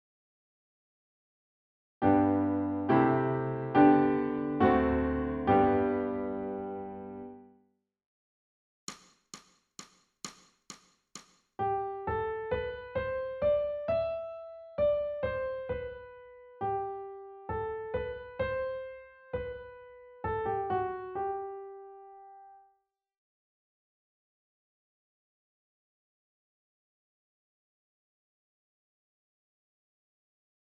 ソルフェージュ 聴音: 1-iii-18